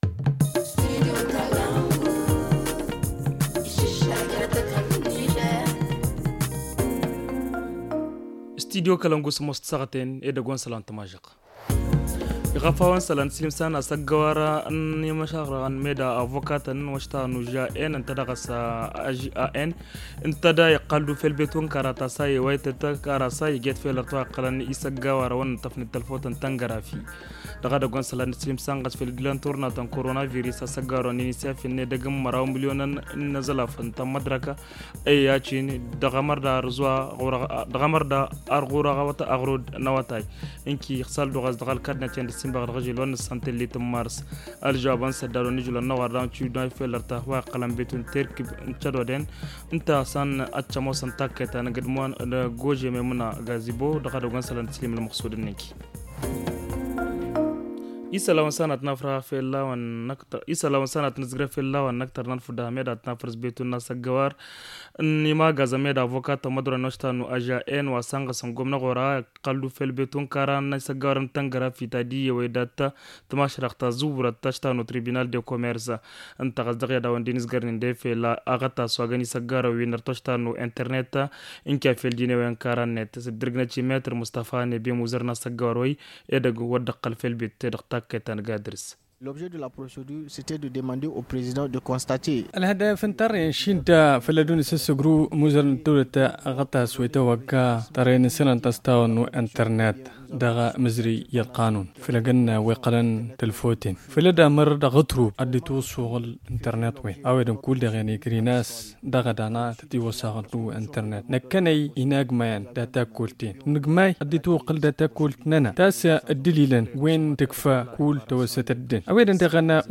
Le journal du 08 mars 2021 - Studio Kalangou - Au rythme du Niger